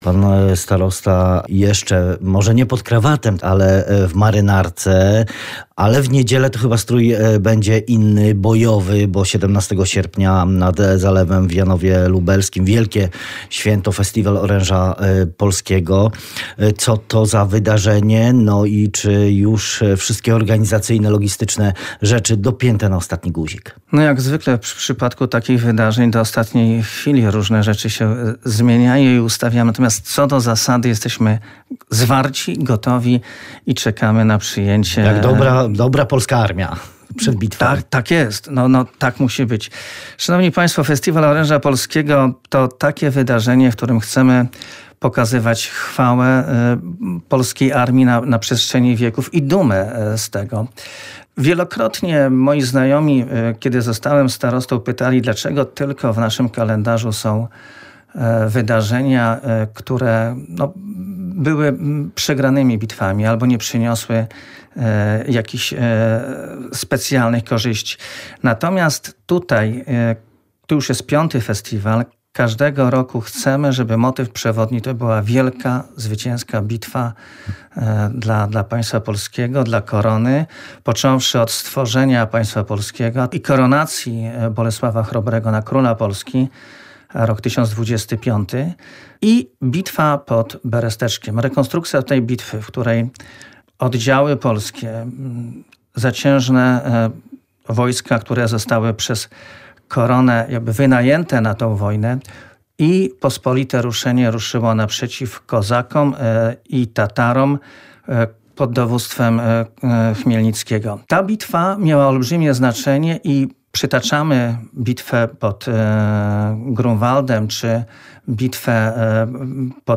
Rozmowa ze starostą janowskim Arturem Pizoniem